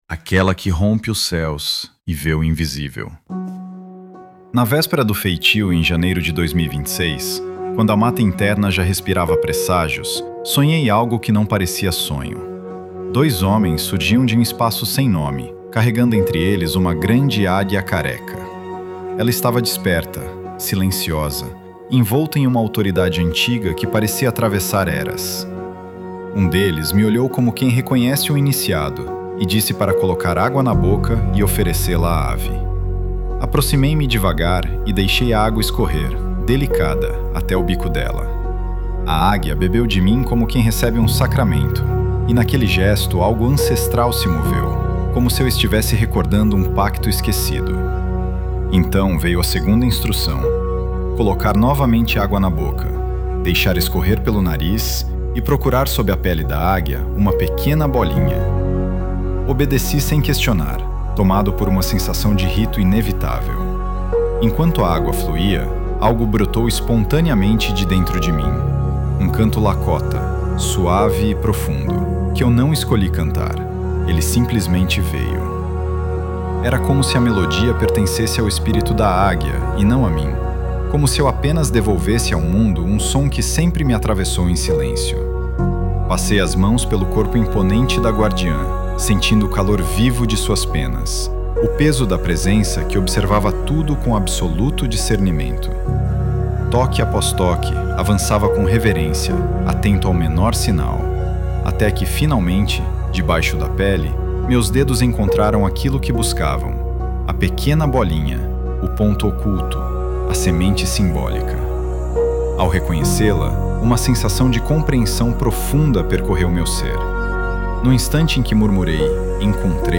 Dificuldade para ler? Ouça a leitura do artigo aqui